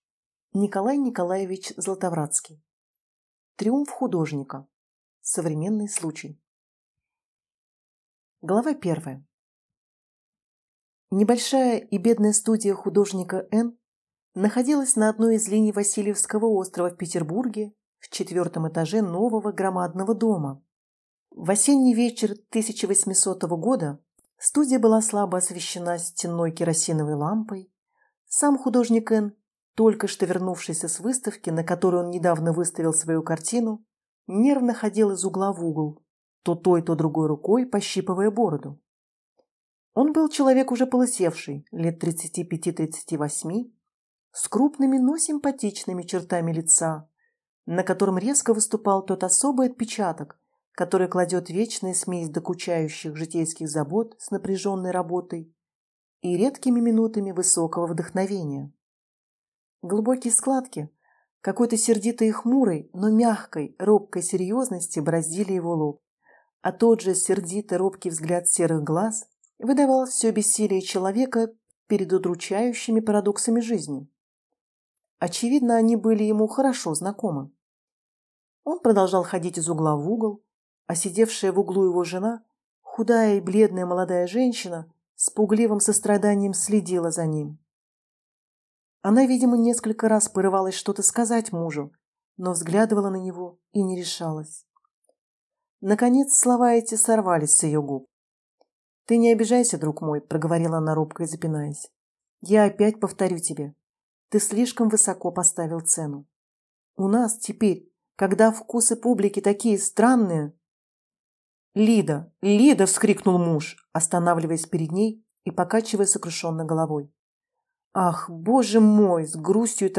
Aудиокнига Триумф художника